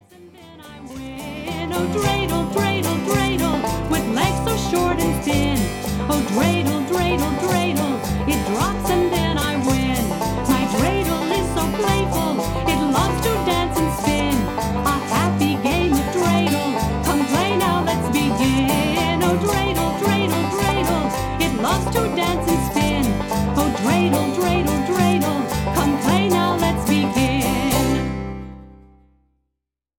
Traditional songs in Hebrew & English.